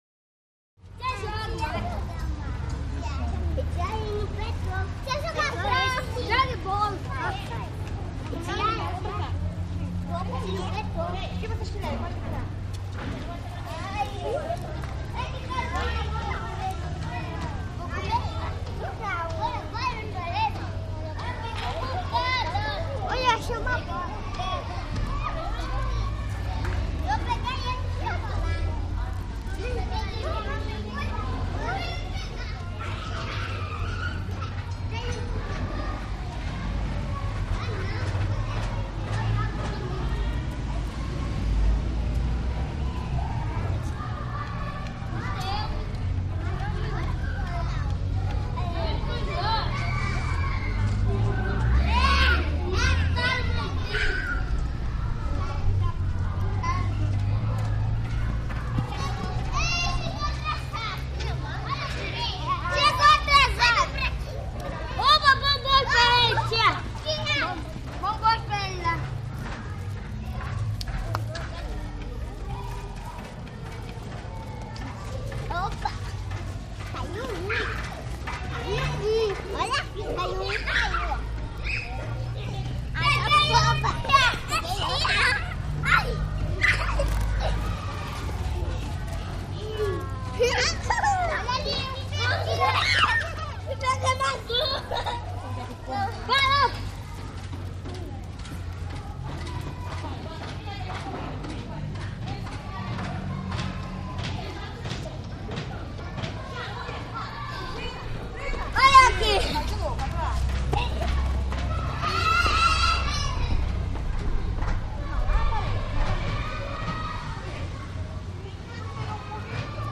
Foreign Town; Brazilian Square Atmosphere. Close Shot Children At Play, Occasional Vehicle, Mid Shot Banging And Hammering From Time To Time And Female Adult.